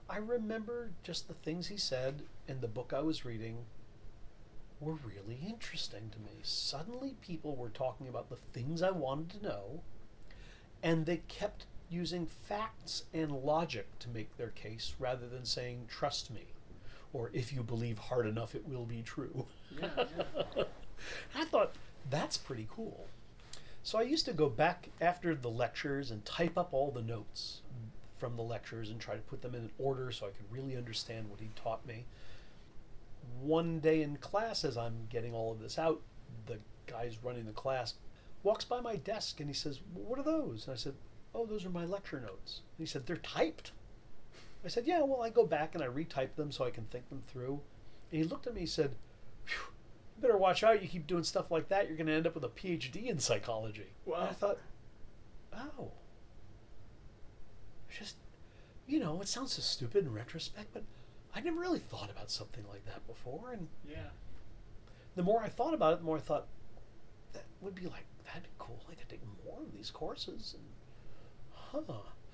Here is Dr. Gilbert describing his experience in that first Psychology course.
gilbert_whyigotaphd_inpsych.mp3